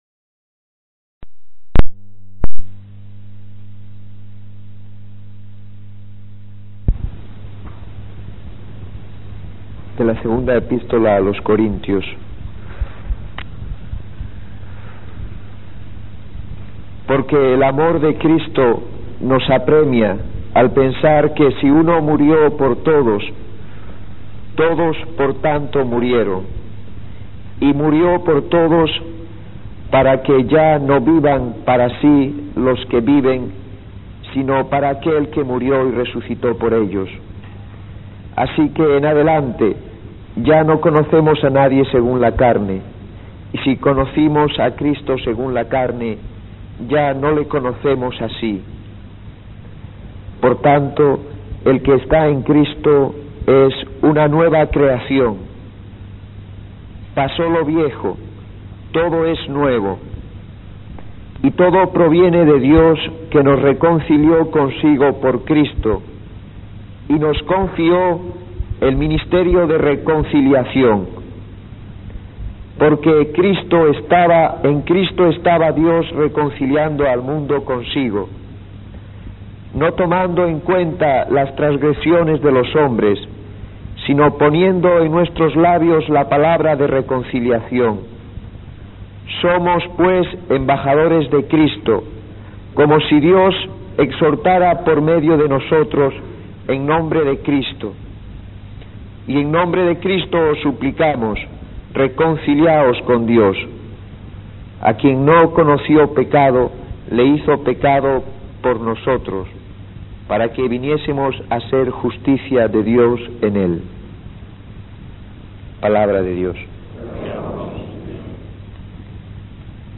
Charlas
en una "Semana de Sacerdotes" a la que asistieron 85